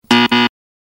Звуки неправильного ответа
На этой странице собрана коллекция звуковых эффектов, обозначающих неправильный ответ или неудачу.
Звук неверного ответа в сто к одному